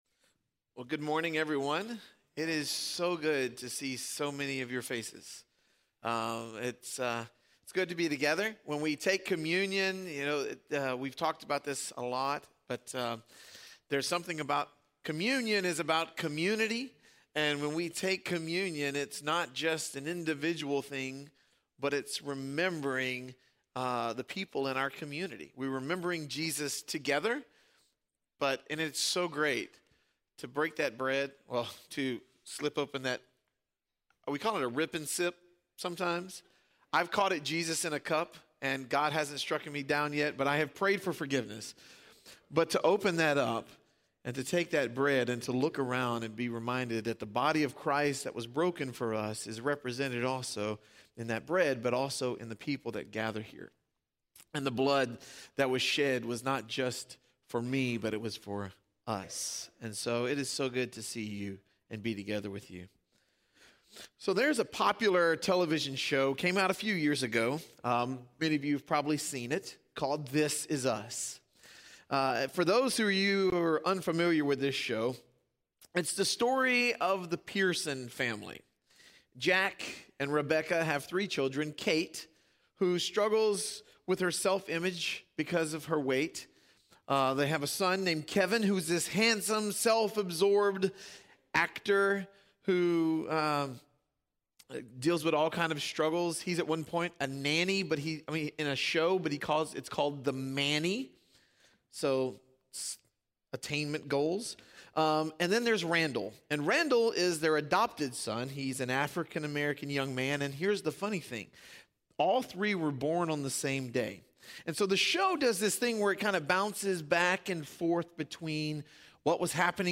In this sermon, we dive into what it means to love God with a...